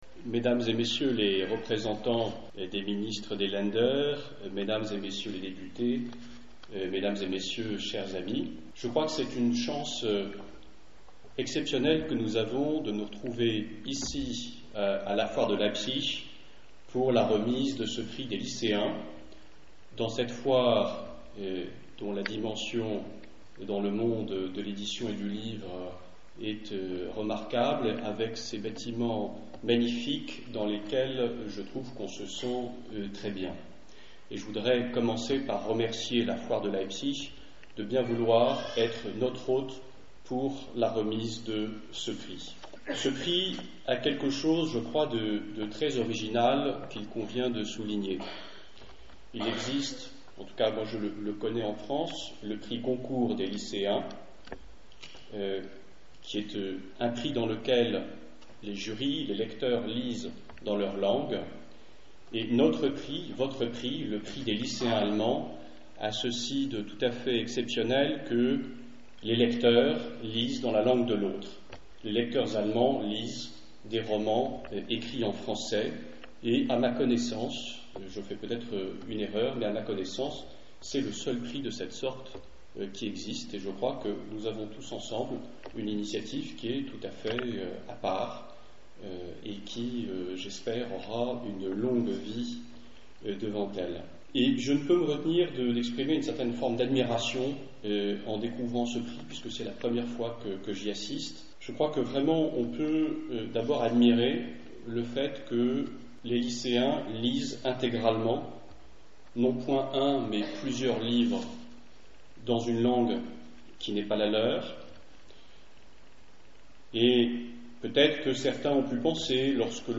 Dans son discours le Conseiller Culturel de l’Ambassade de France en Allemagne, lors de la cérémonie de la remise du Prix des lycéens allemands 2008 vendredi 14 mars sur la Forie des livres de Leipzig, M. Jean d’Haussonville, a exprimé l’attention toute particulière qui revient ce projet.